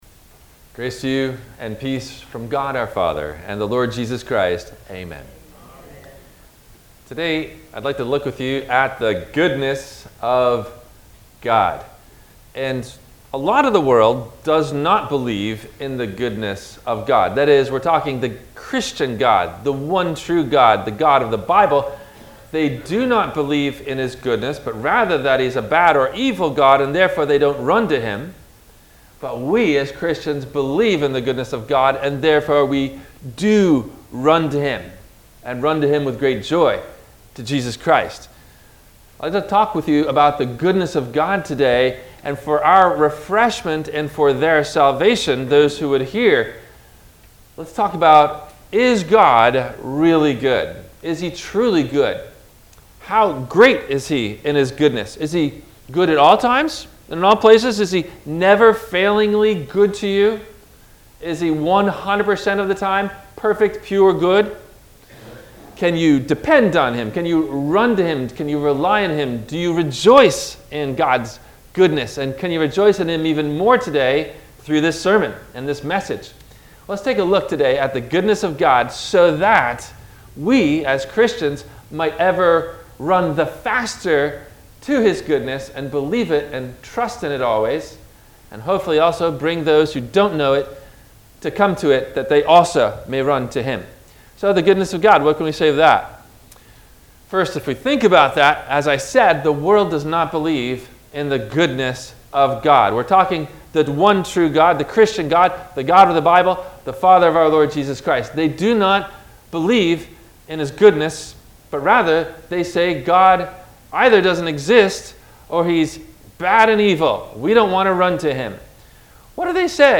Are There Degrees of Punishments and Rewards? – WMIE Radio Sermon – August 08 2022
No Questions asked before the Message.